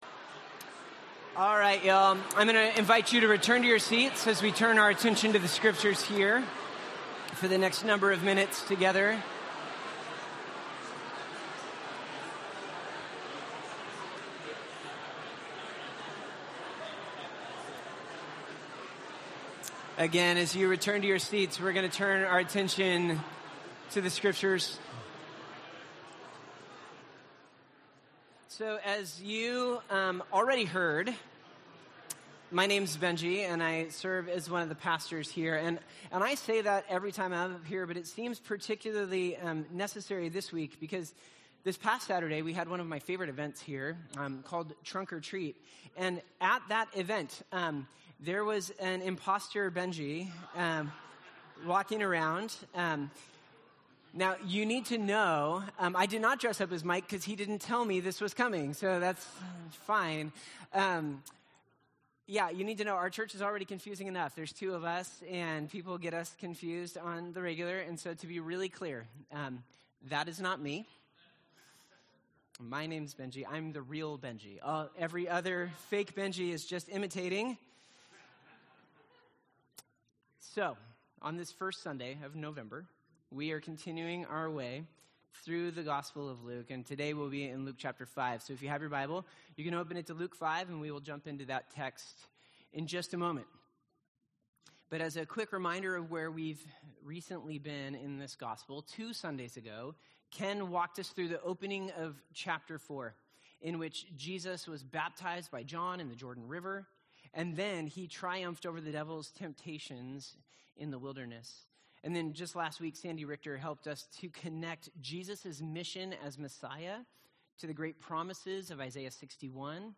Gospel of Luke Passage: Luke 5:1-11 Service Type: Sunday « The Good News in Nazareth The Healing of a Cast-out